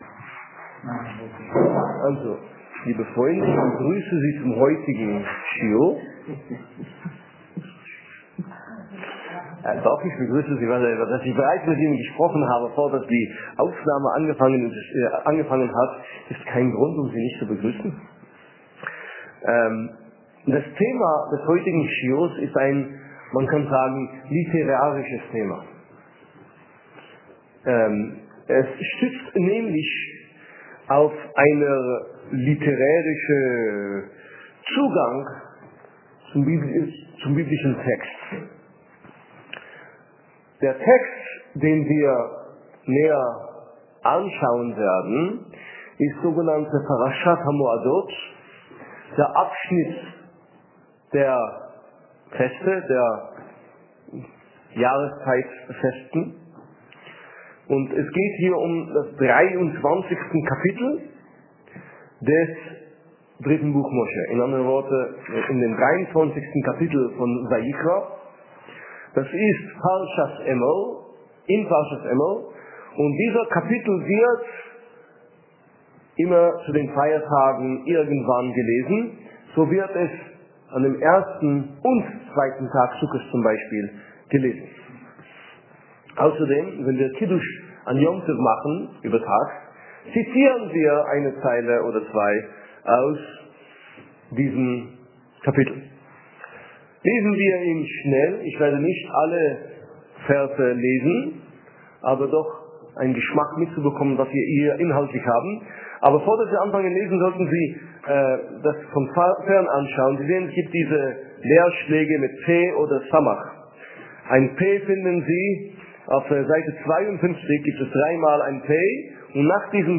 Wieviel Feste feiern wir an Sukkot (Audio-Schiur)